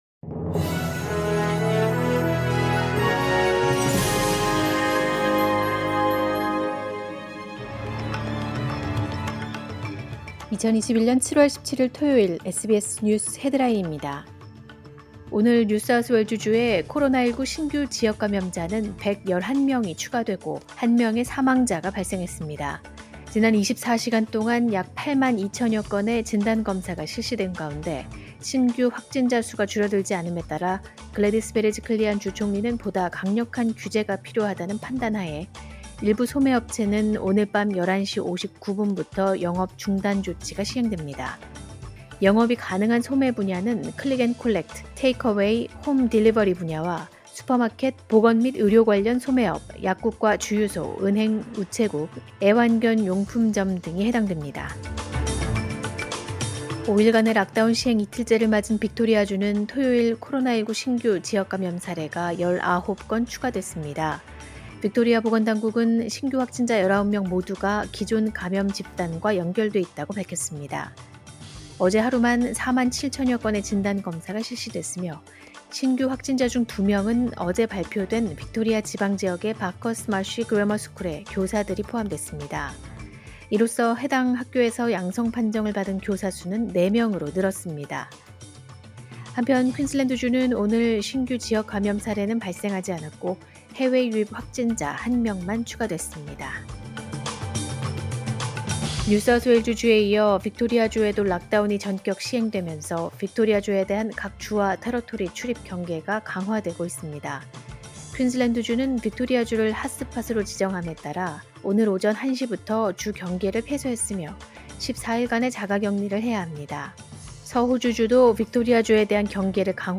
2021년 7월 17일 토요일 SBS 뉴스 헤드라인입니다.